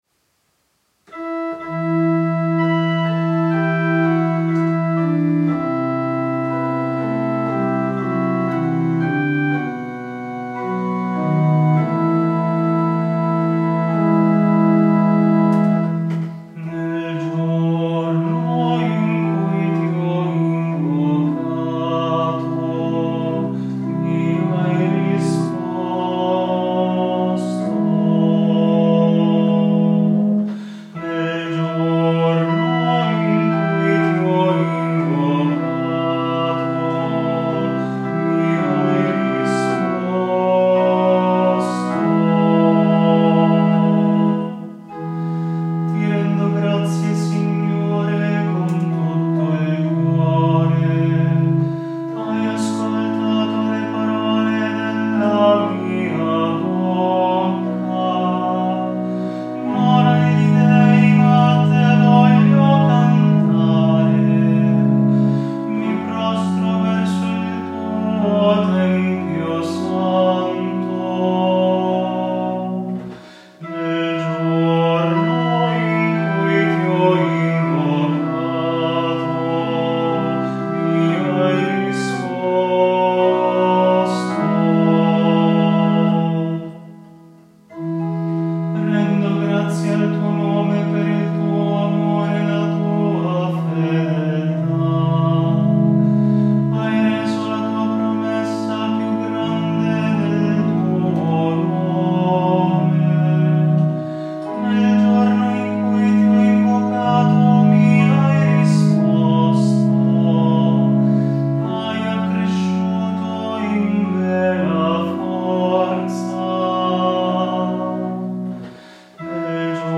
Salmo Responsoriale